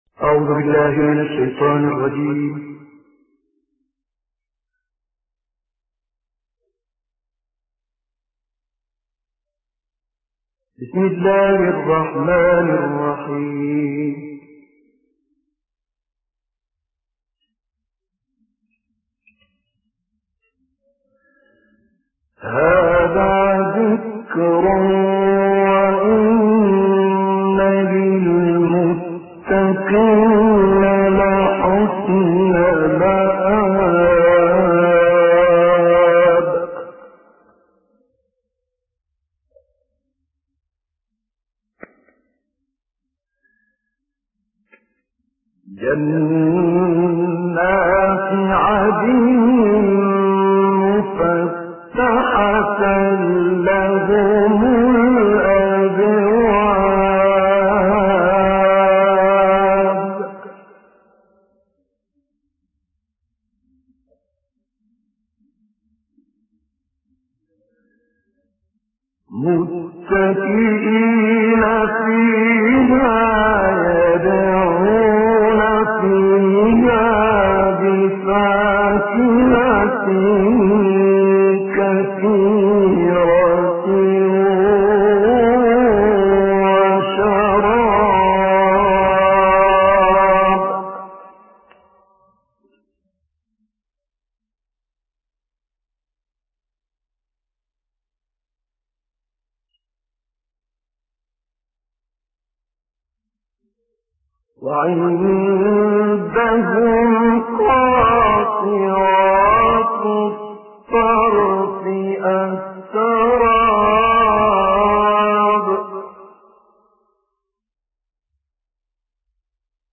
دانلود قرائت سوره های ص آیات 50 تا آخر و زمر آیات 1 تا 6 - استاد طه الفشنی
قرائت-سوره-های-ص-آیات-50-تا-آخر-و-زمر-آیات-1-تا-6-استاد-طه-الفشنی.mp3